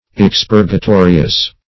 expurgatorious - definition of expurgatorious - synonyms, pronunciation, spelling from Free Dictionary
Search Result for " expurgatorious" : The Collaborative International Dictionary of English v.0.48: Expurgatorious \Ex*pur`ga*to"ri*ous\, a. Expurgatory.